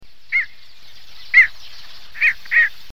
Crabier chevelu, ardeola ralloides
crabier.mp3